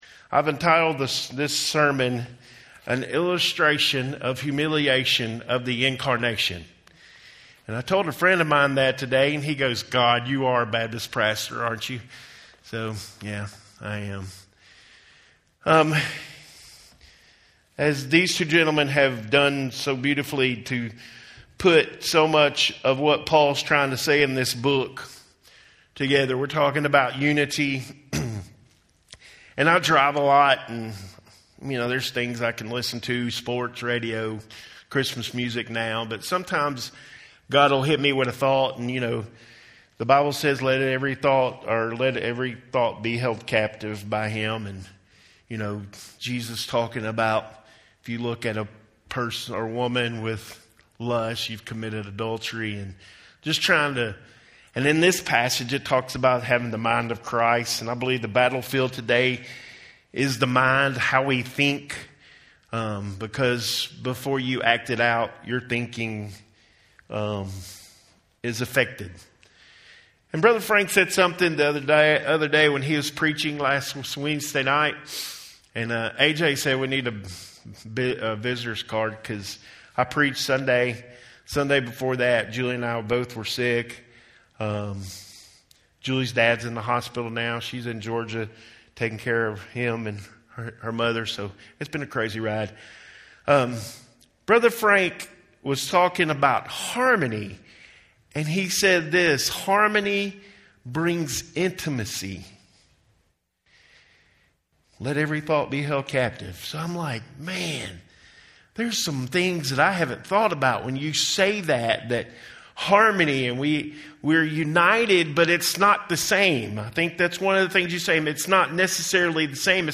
Home › Sermons › An Illustration of Humiliation of the Incarnation